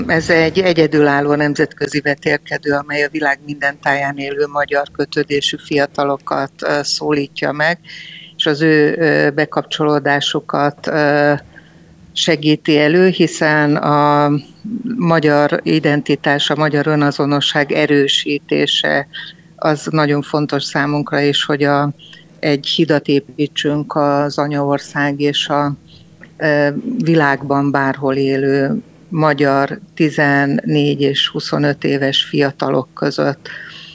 Dr. Szili Katalin rádiónknak a Magyar fiatalok világjátéka program céljairól mesélt.